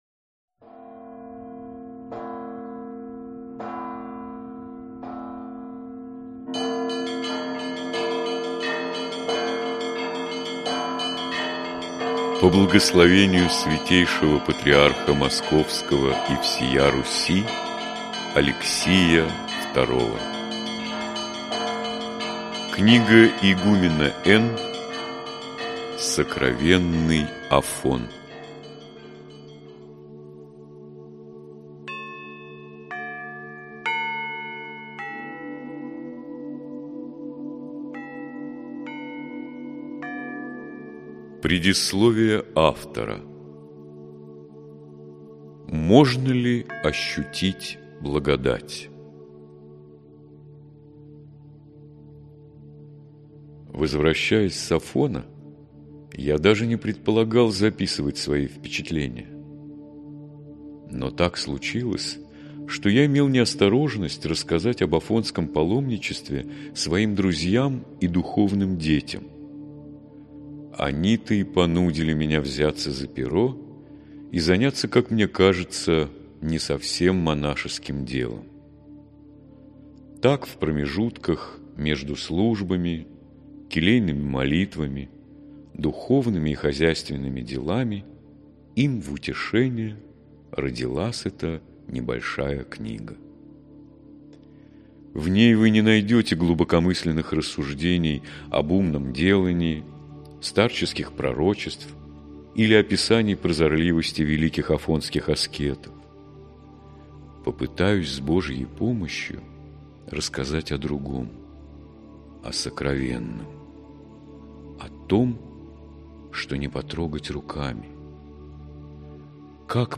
Аудиокнига Сокровенный Афон | Библиотека аудиокниг